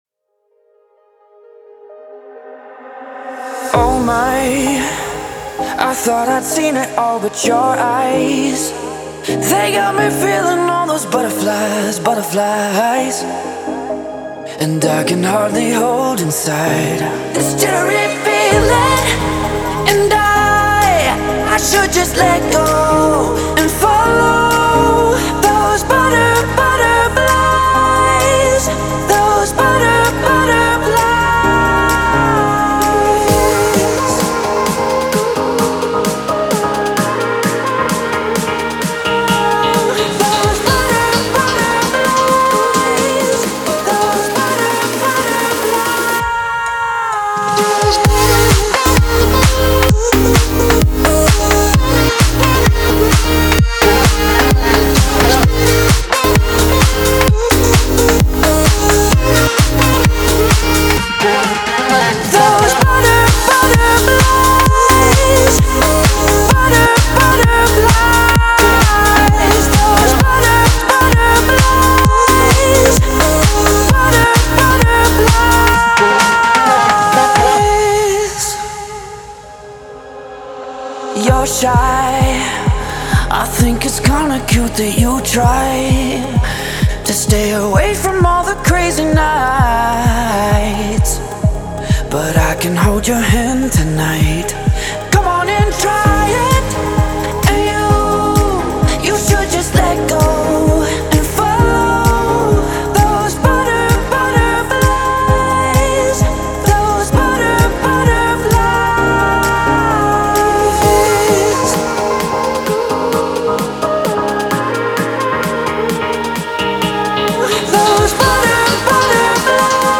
pop and edm